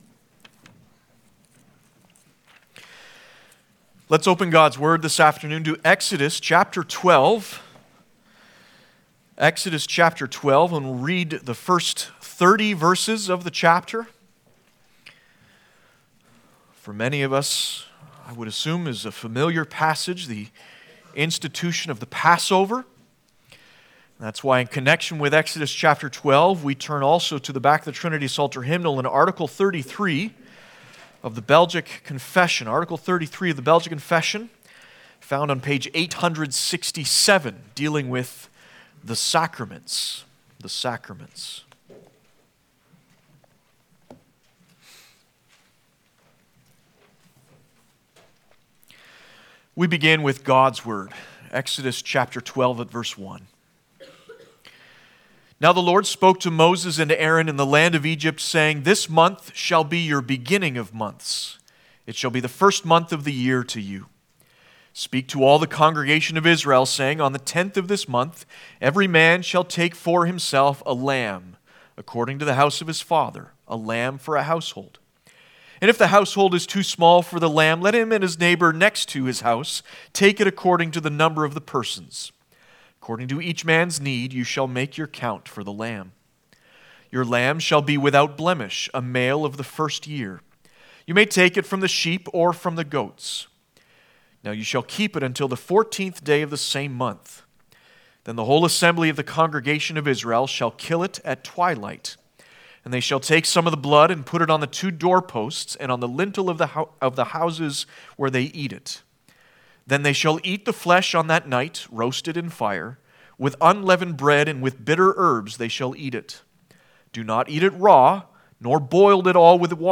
Passage: Exodus 12:1-30 Service Type: Sunday Afternoon